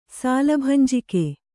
♪ sāla bhanjike